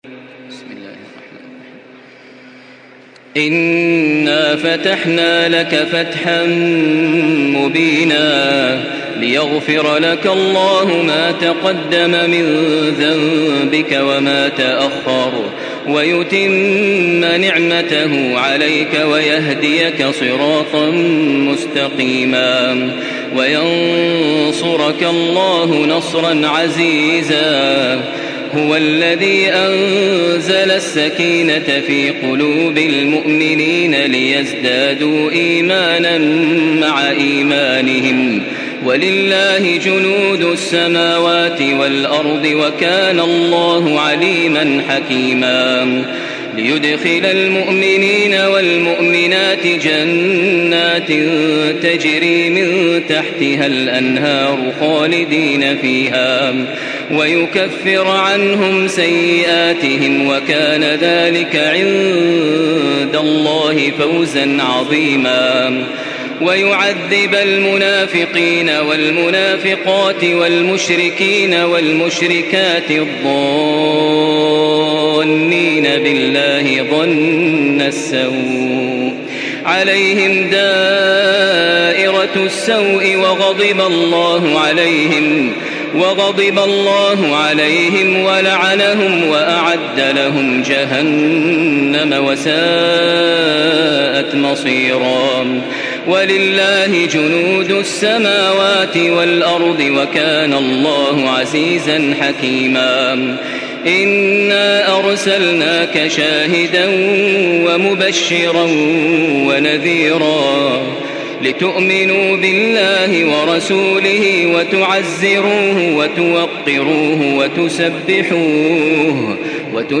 Surah Fetih MP3 by Makkah Taraweeh 1435 in Hafs An Asim narration.
Murattal Hafs An Asim